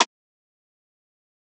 Rimshot [1].wav